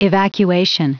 Prononciation du mot evacuation en anglais (fichier audio)
Prononciation du mot : evacuation